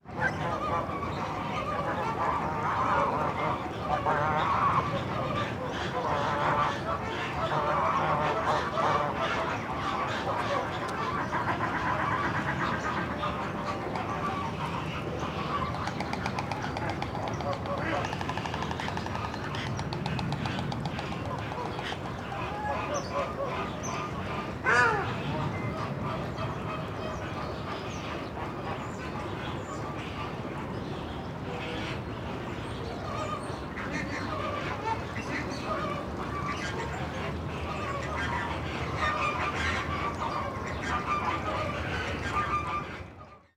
Zoo de Madrid: aves acuáticas 4: ánade, cigüeña, flamenco, ganso, grulla, pelícano, tarro